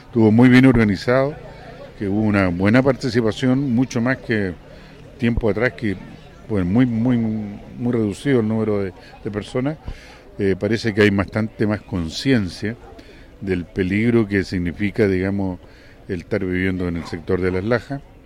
A su vez, el director regional de Sernageomin, Carlos Johnson, señaló que en esta ocasión participaron como observadores, destacando la organización y la coordinación entre el municipio y los organismos de emergencia y técnicos que apoyaron.